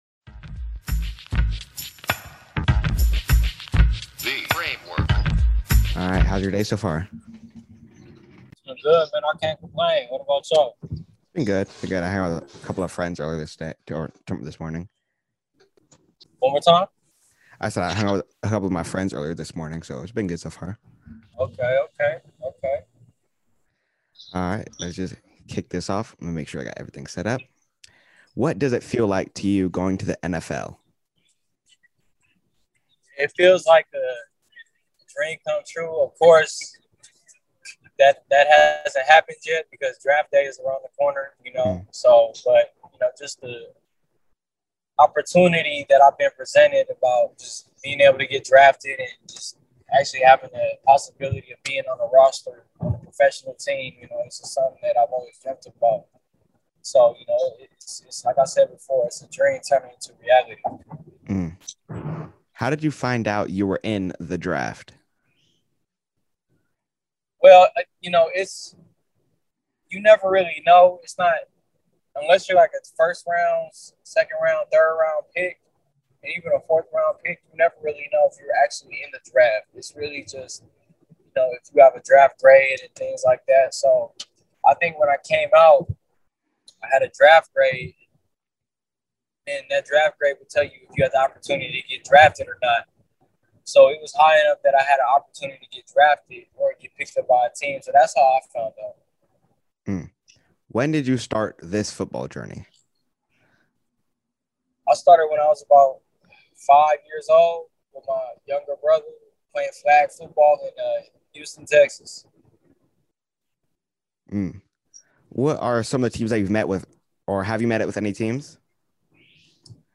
Artist Interview